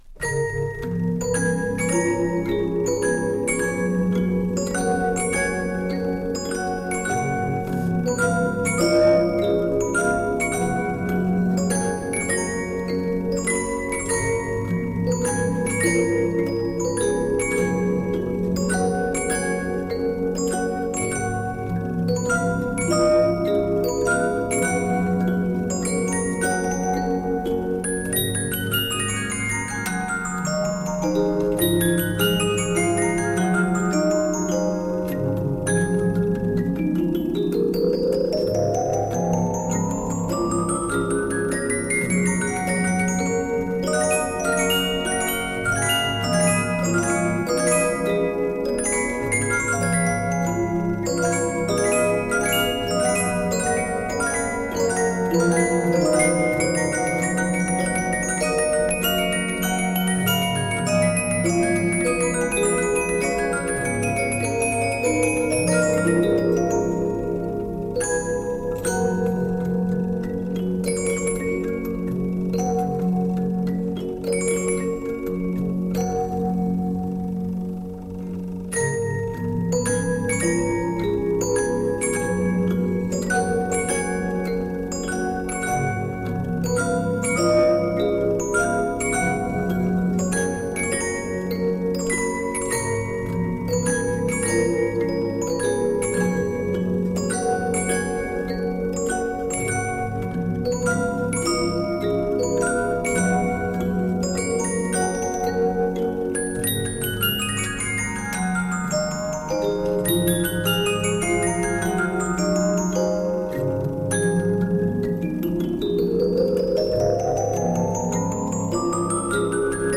music box album